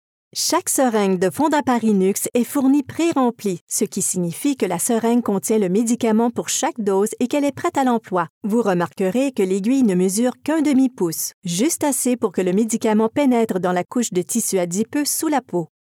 Franko Kanadier)
Unternehmensvideos
Eine klare, natürliche und vertrauenswürdige französisch-kanadische Synchronsprecherin für E-Learning, Synchronisation, Unternehmenspräsentationen, Telefonansagen (IVR) und Produktdemonstrationsvideos.